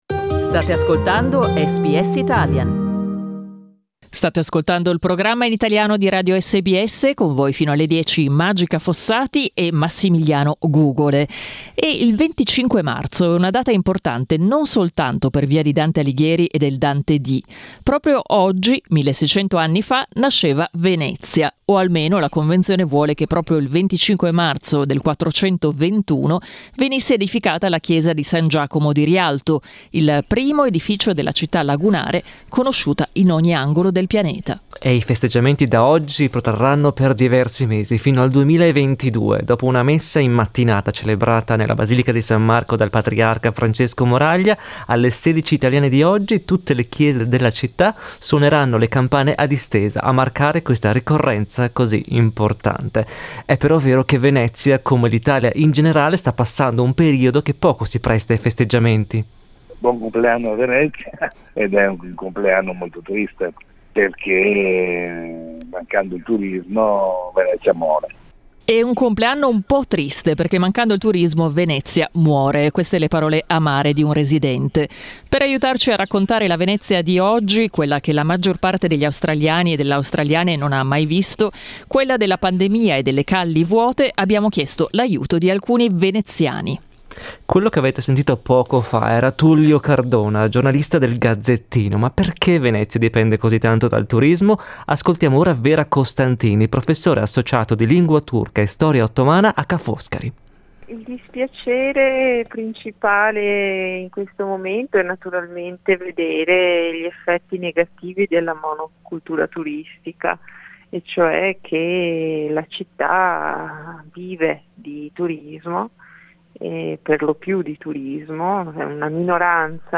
Venezia compie 1600 anni in piena pandemia. Sei veneziani raccontano ai nostri microfoni la città ai tempi del COVID, tra le calli vuote di persone, i piccioni che non ci sono più e un rumoroso silenzio che non si era mai sentito prima.